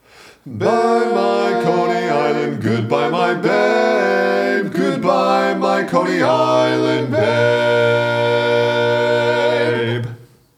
Other part 2: